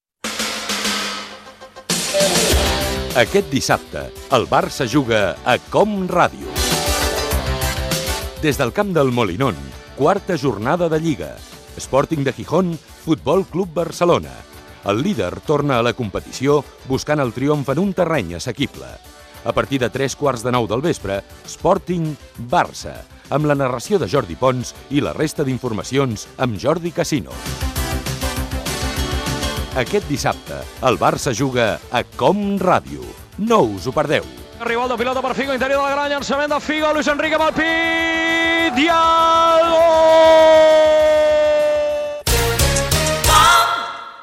Promoció de la transmissió del partit de la lliga masculina de futbol Sporting de Gijón-Futbol Club Bracelona.
Esportiu